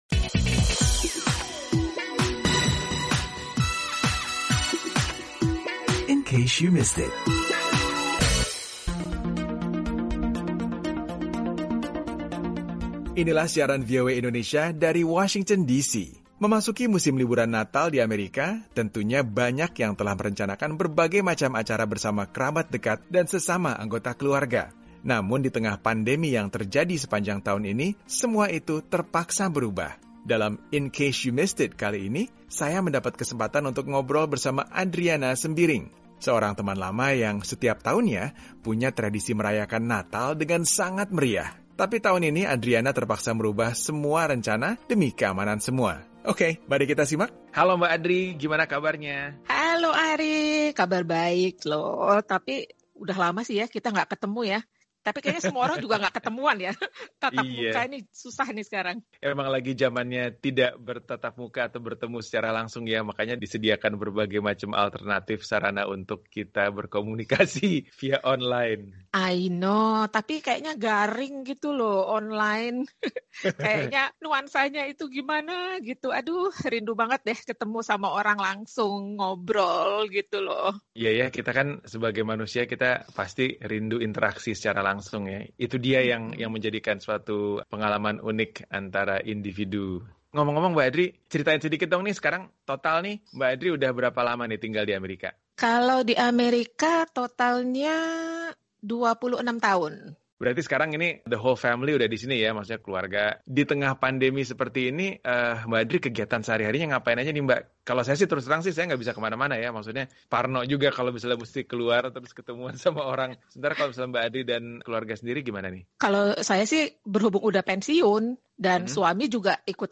berbincang dengan diaspora Indonesia di AS